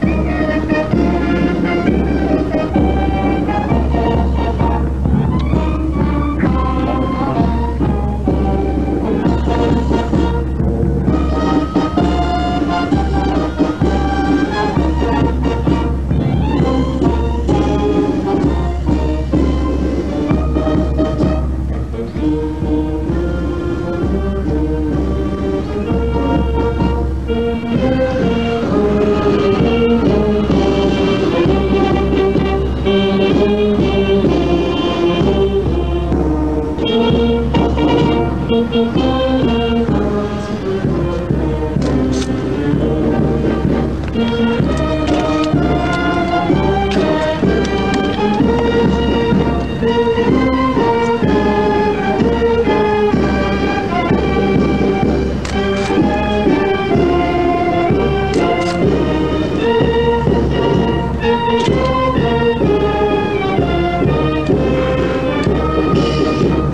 1985년 공연